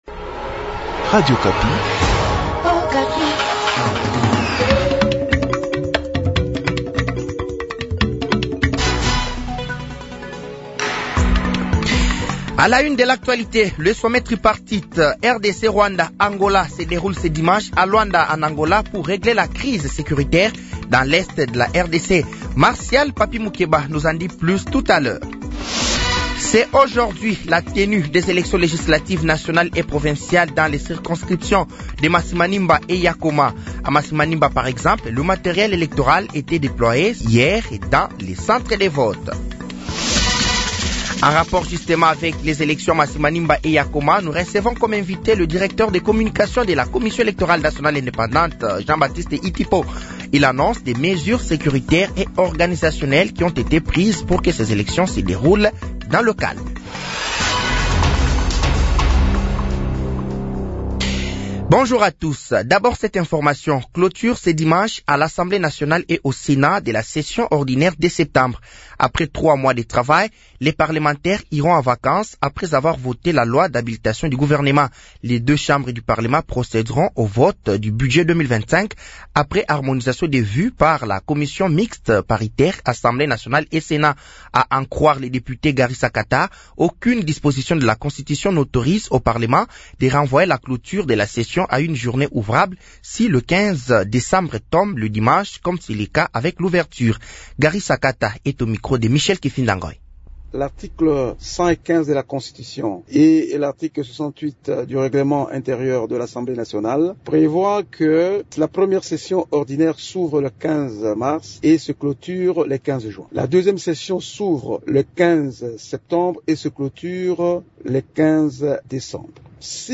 Journal français de 7h de ce dimanche 15 décembre 2024